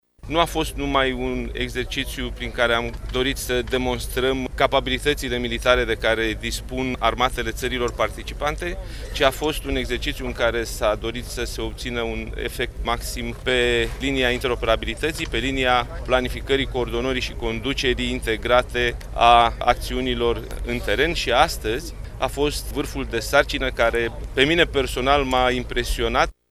La rândul său şeful Statului Major General, Nicolae Ciucă, a declarat că prin acest exercițiu s-a dorit să se obțină un efect maxim pe linia interoperabilității: